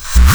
REVERSBRK1-R.wav